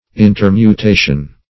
\In`ter*mu*ta"tion\